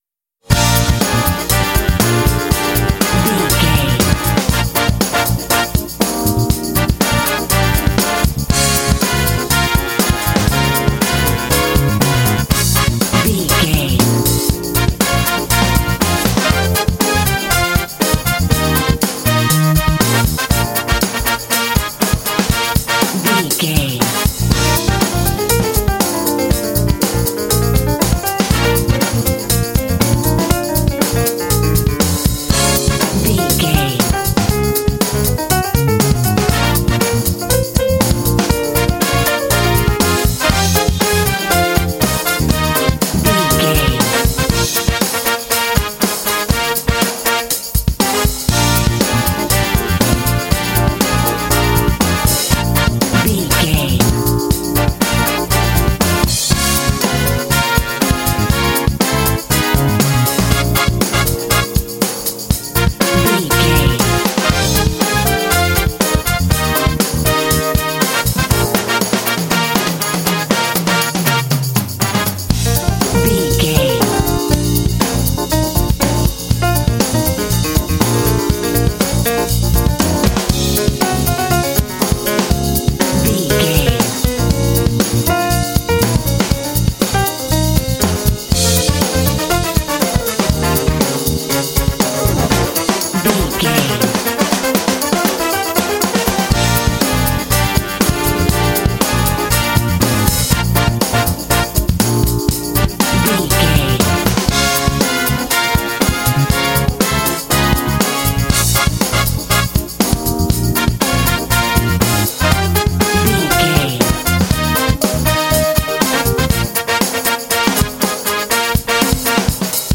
Uplifting
Aeolian/Minor
B♭
groovy
cheerful/happy
driving
brass
bass guitar
saxophone
electric organ
drums
piano
jazz